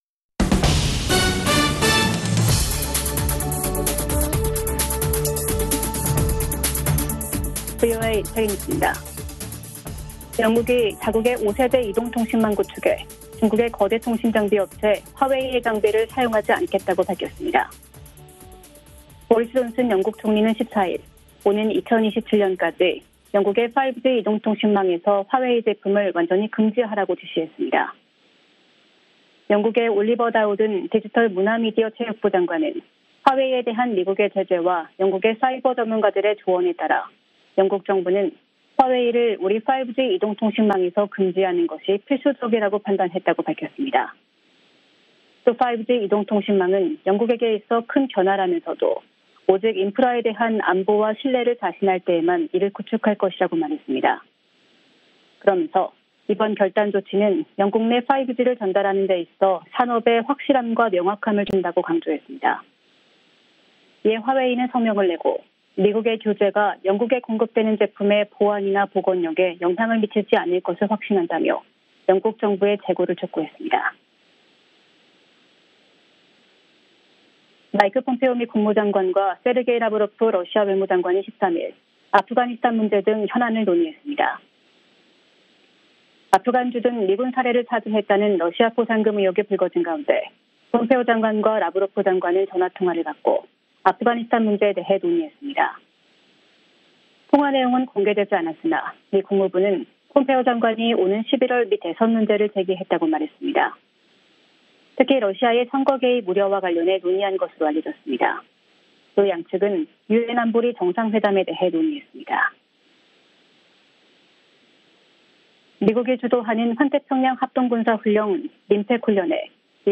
VOA 한국어 아침 뉴스 프로그램 '워싱턴 뉴스 광장' 2020년 7월 15일 방송입니다. 미국 의회가 제시한 2021회계연도 정부 예산안에서 한반도 외교안보의 역점은 ‘미사일, 주한미군, 북한 인권’입니다. 남북관계 경색과 신종 코로나바이러스 감염증 사태의 장기화가 겹치면서 올들어 남북 이산가족 교류가 사실상 중단됐습니다.